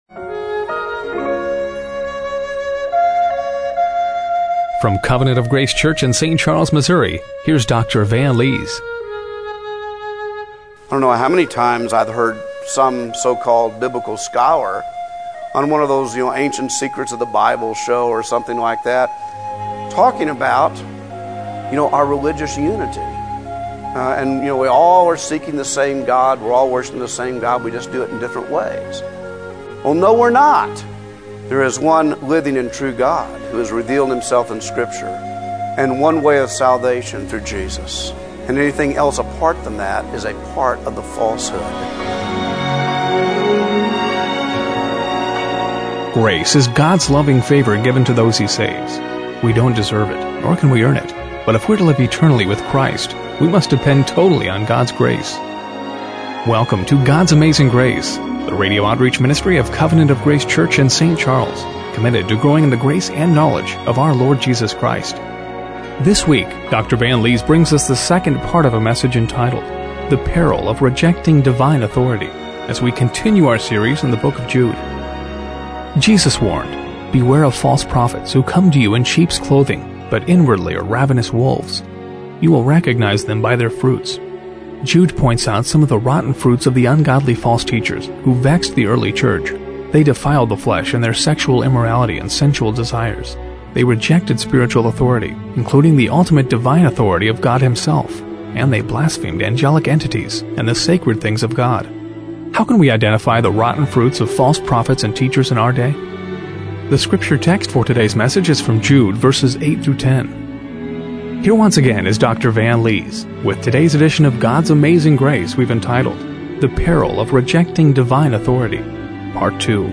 Jude 1:4-7 Service Type: Radio Broadcast How can we identify the rotten fruits of false prophets and teachers in our day?